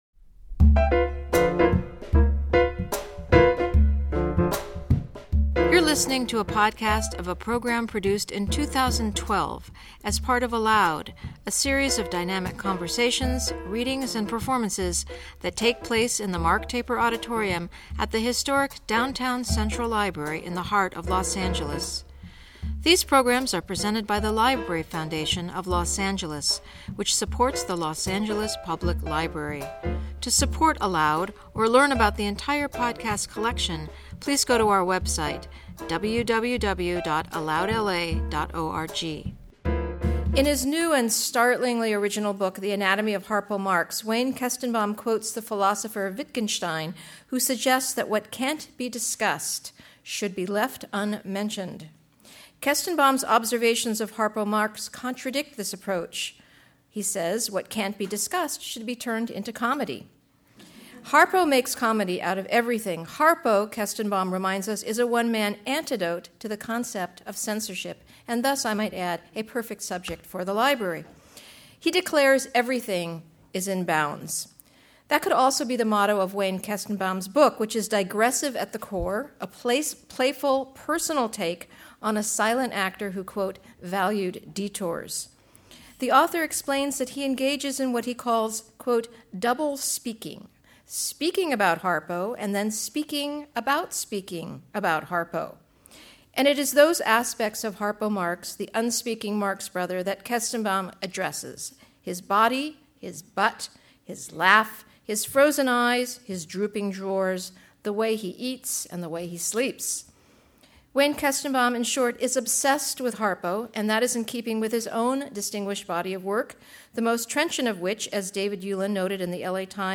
In conversation